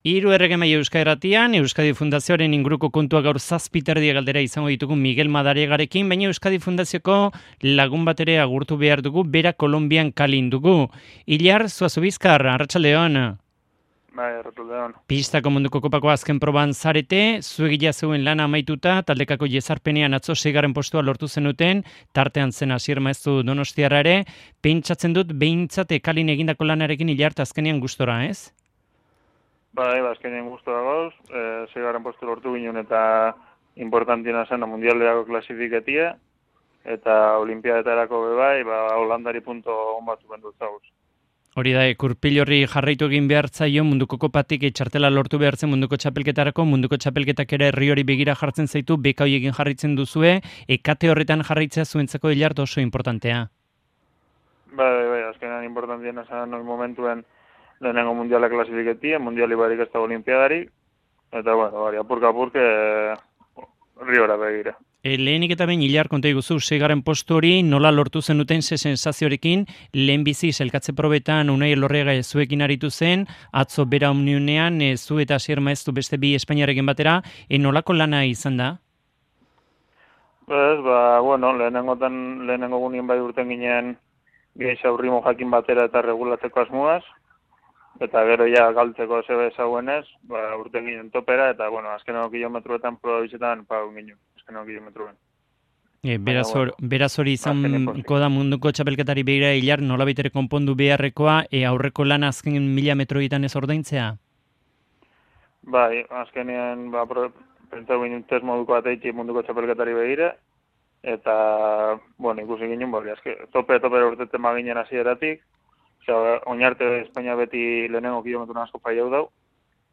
pozik mintzatu zaigu Cali-tik (Colombia). Pistako munduko kopako proban hartu du parte, eta munduko txapelketarako sailkapena lortu dute.